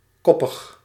Ääntäminen
IPA: /ˈkɔpəx/